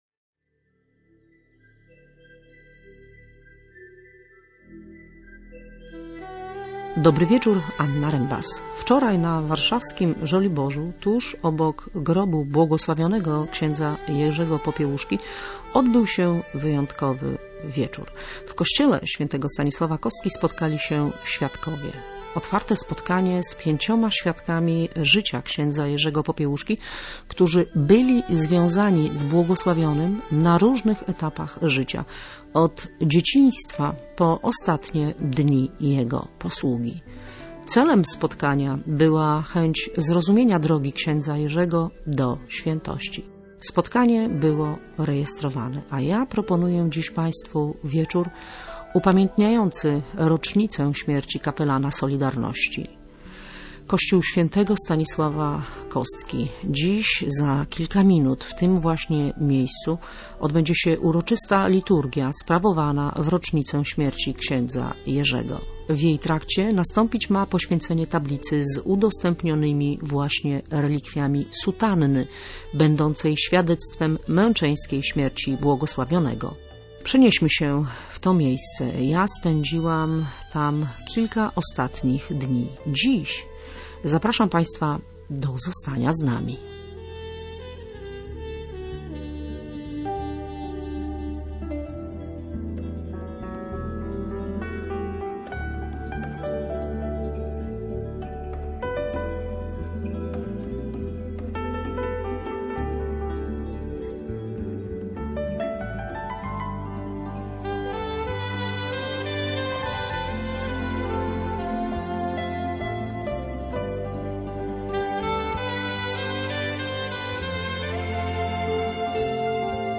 „Zwykły kapłan” – radiowy portret błogosławionego księdza Jerzego Popiełuszki
Nasza reporterka oprowadziła słuchaczy po Sanktuarium św. Stanisława Kostki na Żoliborzu i grobie błogosławionego księdza Jerzego Popiełuszki. Odwiedziła też z nimi warszawskie sanktuarium kapłana i stworzone tam muzeum.